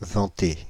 Ääntäminen
Ääntäminen France (Île-de-France): IPA: /vɑ̃.te/ Haettu sana löytyi näillä lähdekielillä: ranska Käännöksiä ei löytynyt valitulle kohdekielelle.